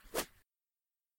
弓与箭 " 箭的飞翔2
描述：麦克风：SM58DAW二手 Audacity＆amp; ReaperObjects使用：塑料1米棒方法：简单的速度移动的woosh声音，通过摆动塑料棒通过麦克风记录
标签： 飞掠 飞掠 woosh 射箭
声道立体声